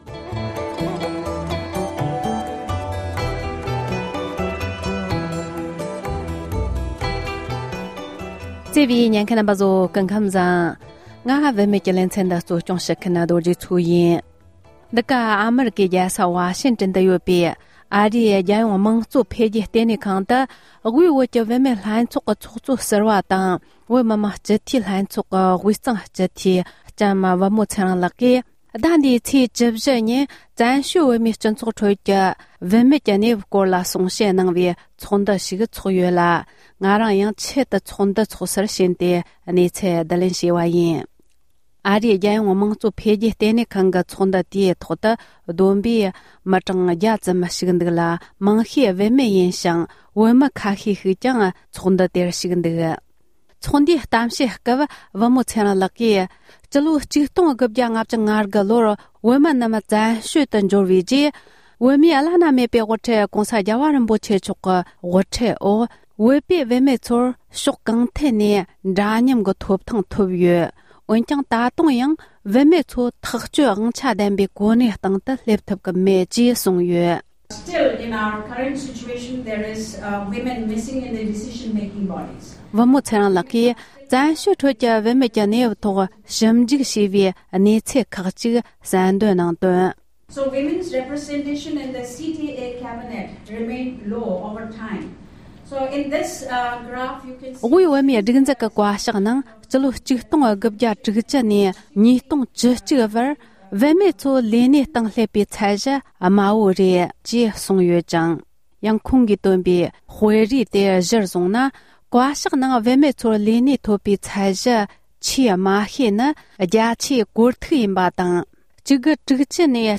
བོད་མི་མང་སྤྱི་འཐུས་སྦི་ཚེ་རིང་ལགས་ཀྱིས་གཏམ་བཤད་སྤེལ་བ།